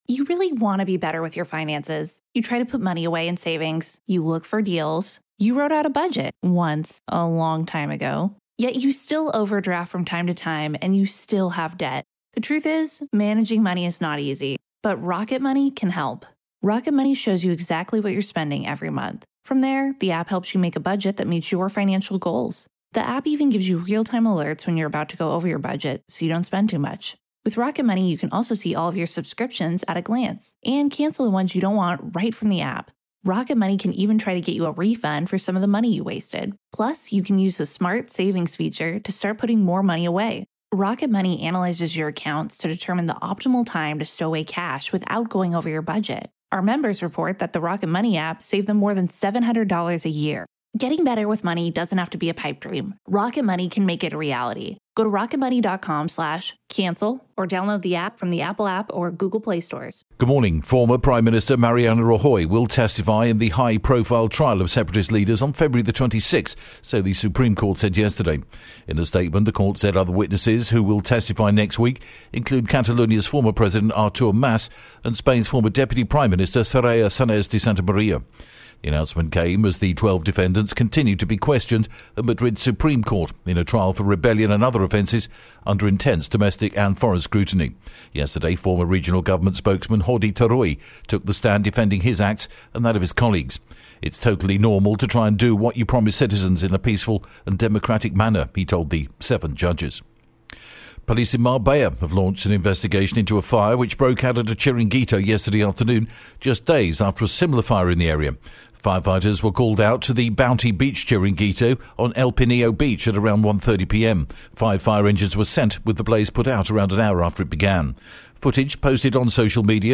The latest Spanish News Headlines in English: February 20th